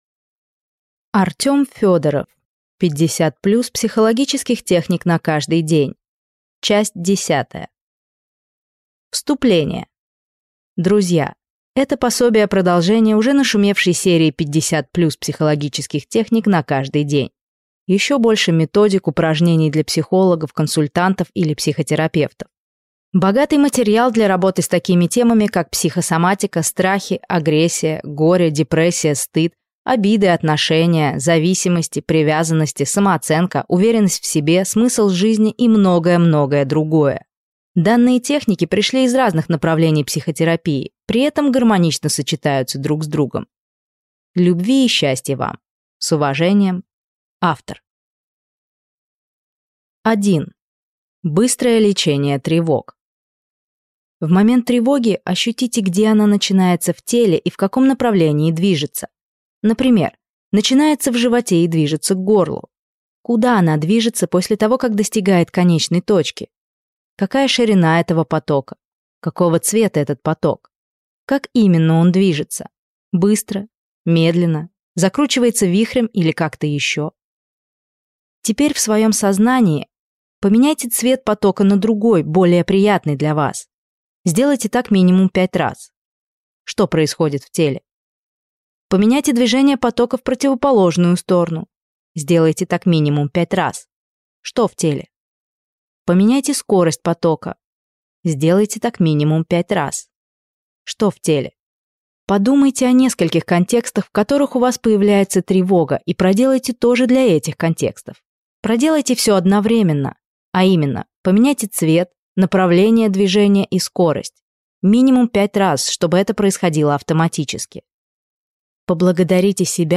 Аудиокнига 50+ психологических техник на каждый день. Часть 10 | Библиотека аудиокниг